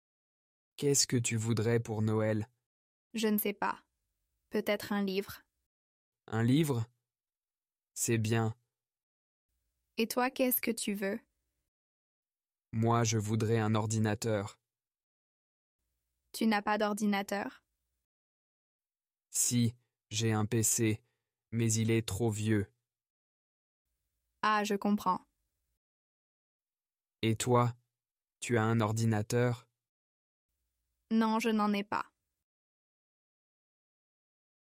Dialogue – Questions simples avec « vouloir » (Niveau A1)